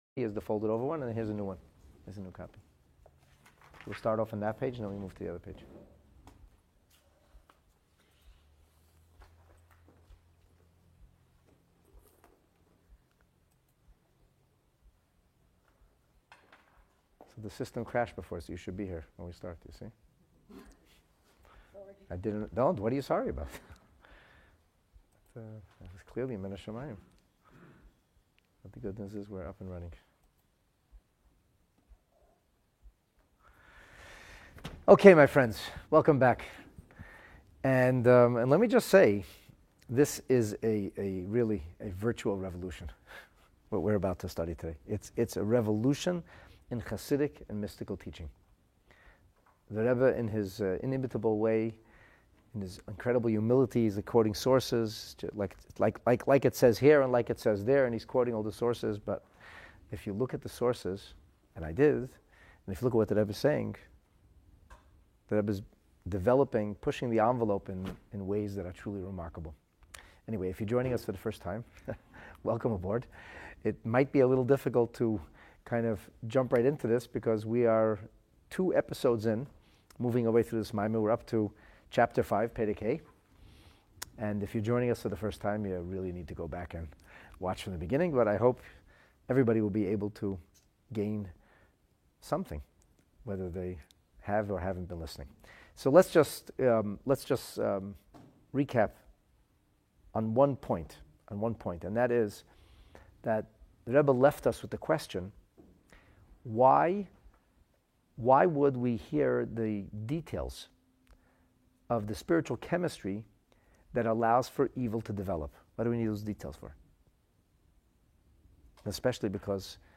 Seeking resolution to the faith conundrum of Evil's existence, a revolutionary idea on the virtual, yet real-time effect of Torah study is presented . The third class of the series (covering chapters five and six) on the Ma’amer continues to broadly address the primary theme in chapter 13 of the original Ma’amer Basi L’Gani (5710).